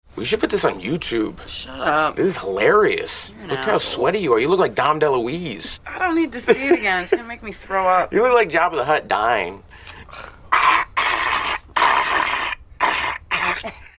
Tags: Roswell actress Alison Knocked Up Katherine Heigl Knocked Up Knocked Up movie clip